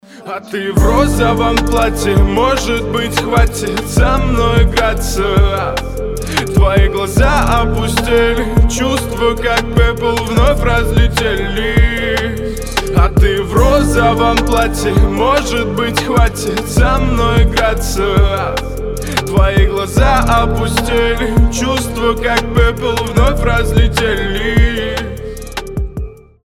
• Качество: 320, Stereo
мужской голос
лирика